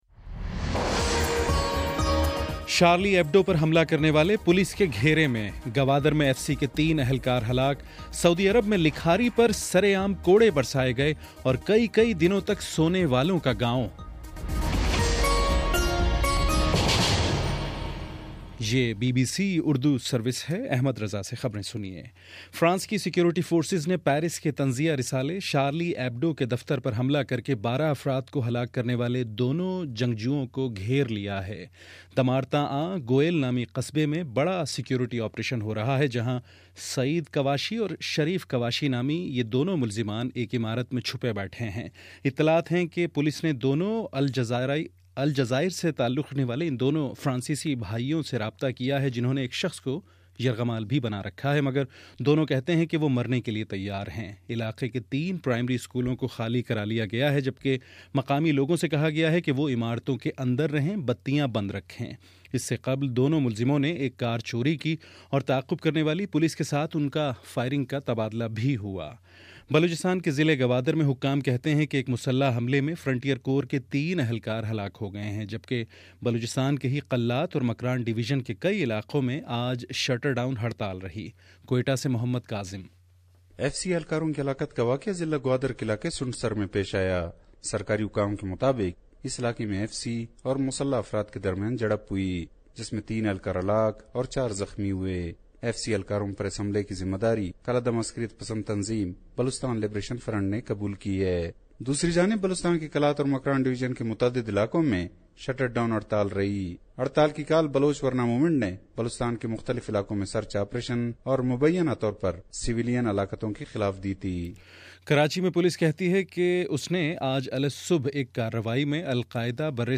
جنوری 09: شام چھ بجے کا نیوز بُلیٹن